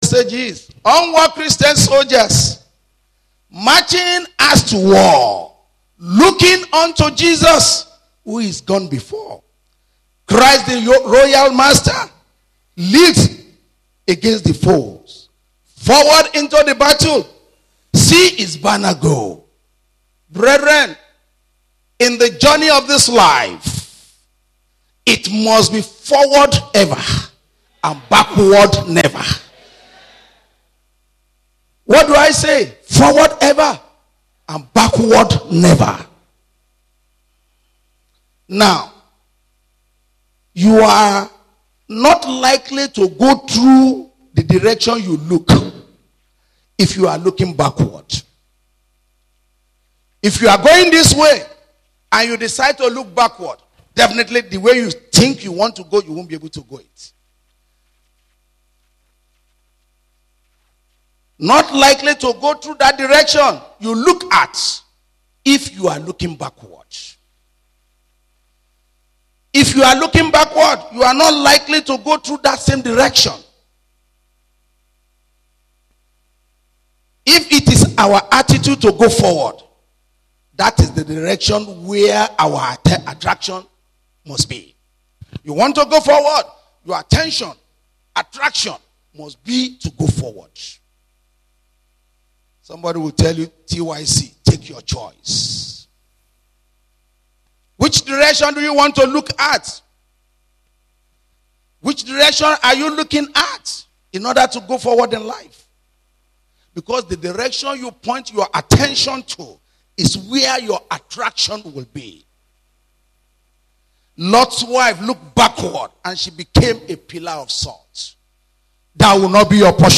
Moving Forward – a Sunday Sermon
Posted in Sunday Service